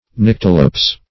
Search Result for " nyctalops" : The Collaborative International Dictionary of English v.0.48: Nyctalops \Nyc"ta*lops\, n. [L., from Gr. nykta`lwps.] One afflicted with nyctalopia.